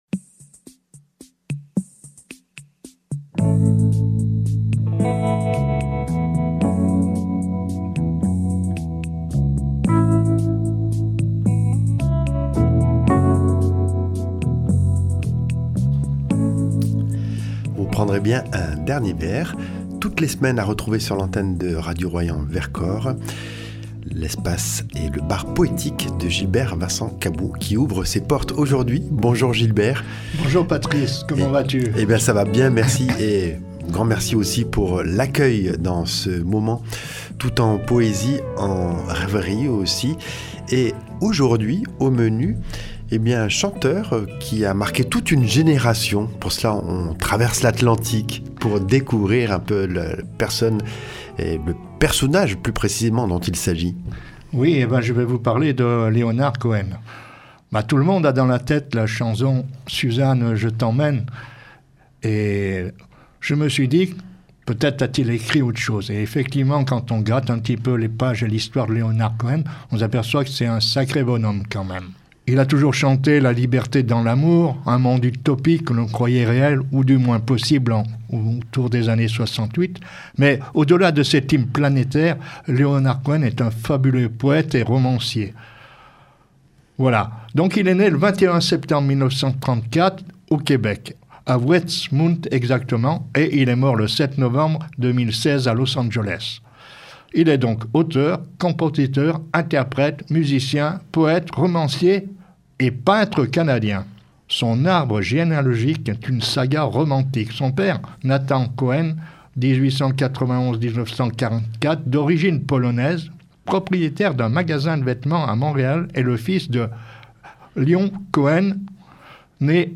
Au programme : lecture d’un poème par semaine accompagnée d’une petite biographie de l’auteur ou l’autrice.